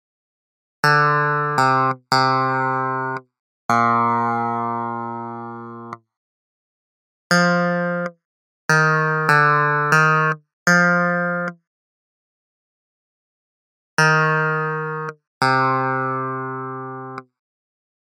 Key written in: F Major
Each recording below is single part only.
a reed organ